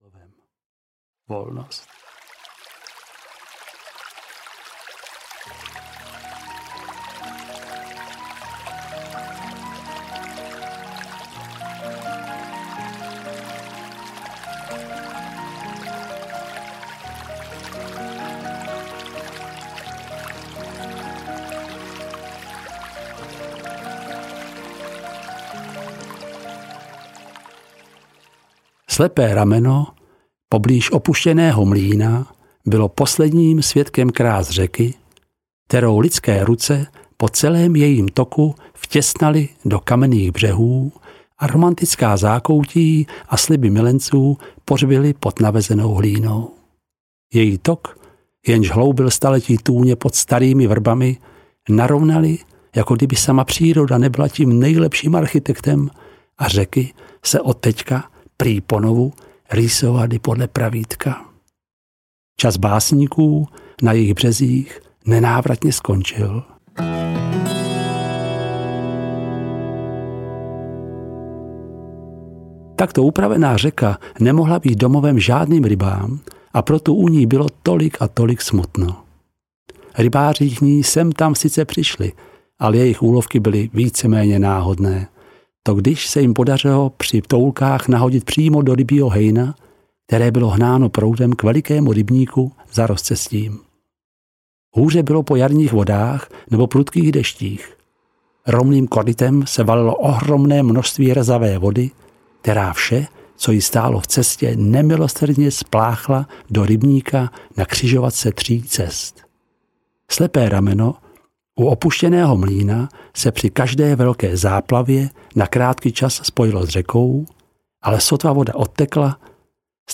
Ryba viděná audiokniha
Ukázka z knihy
ryba-videna-audiokniha